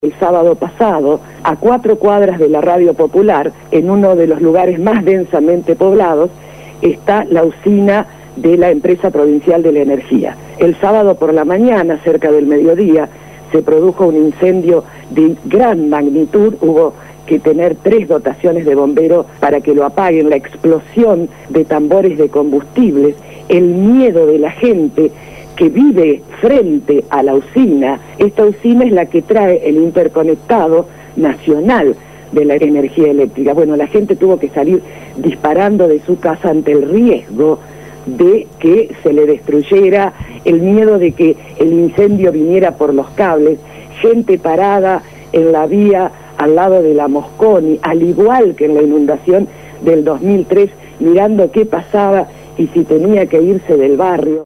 habló esta mañana en el programa Punto de Partida de Radio Gráfica FM 89.3 sobre el panorama de la ciudad de Santa Fe luego de la tormenta de los últimos días.